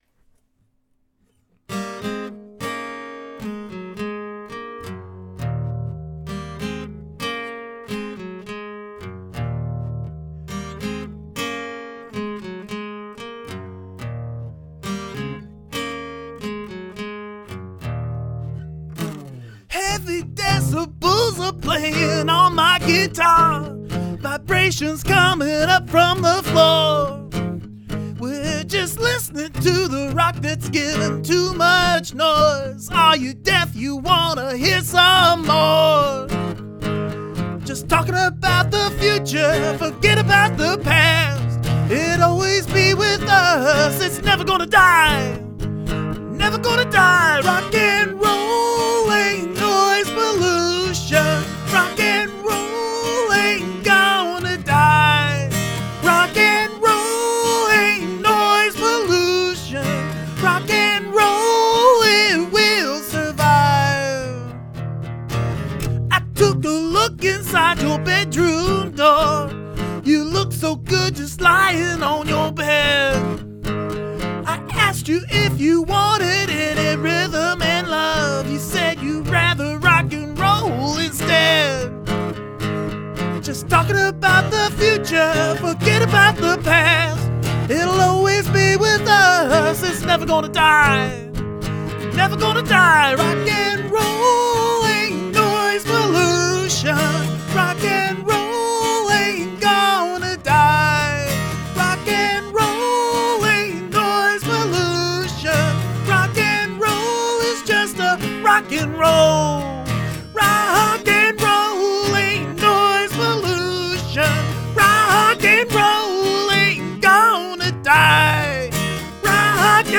Brilliant unplugged version